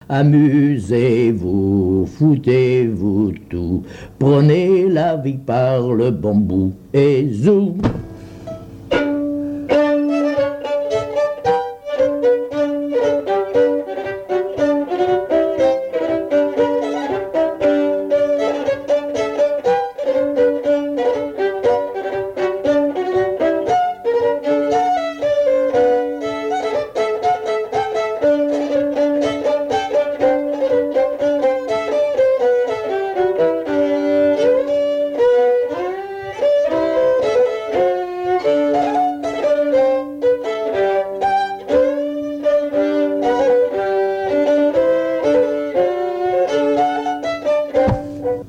danse : polka
Pièce musicale inédite